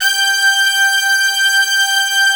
G5 POP BRASS.wav